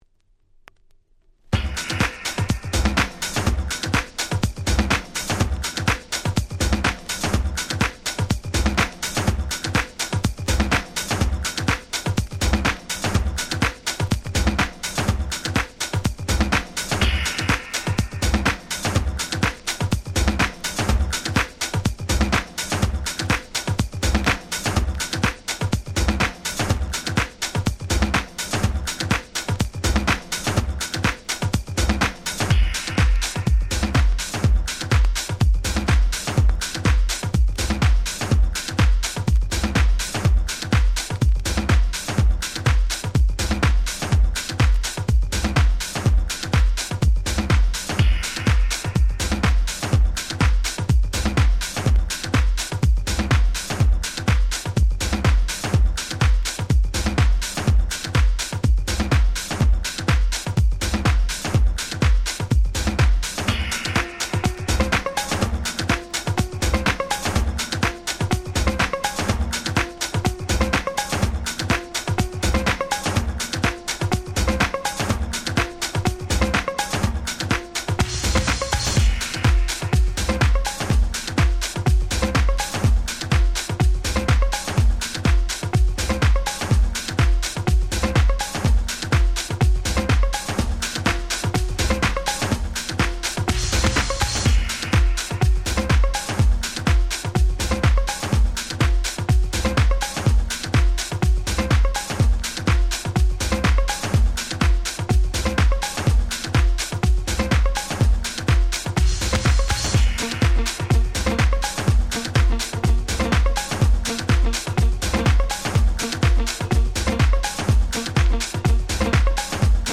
99' House Classics !!
タイトル通りバイオリンの音色でグイグイ引っ張る最強の1曲！！